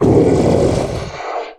spawners_mobs_mummy_death.3.ogg